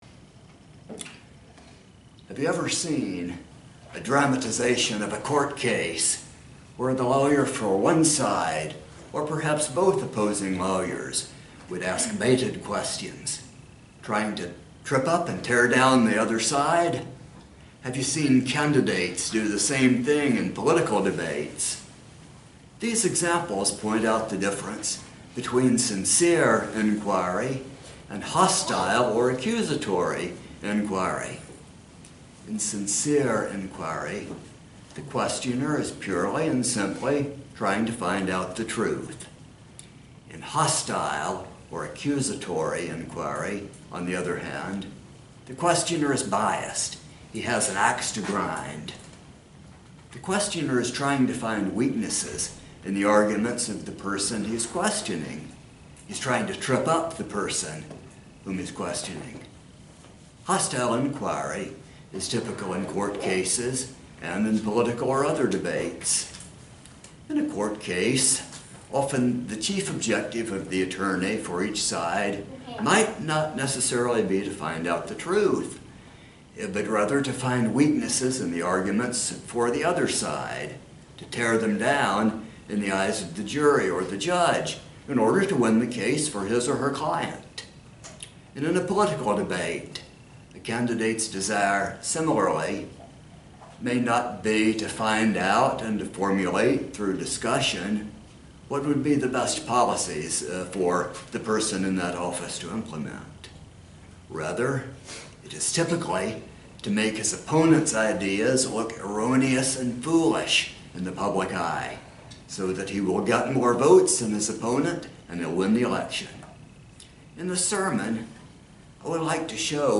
When we are asking questions , are we seeking the truth or instead are we being biased in our questions to be hostile toward the one whom we are questioning? This sermon explores some examples of both.
Given in Roanoke, VA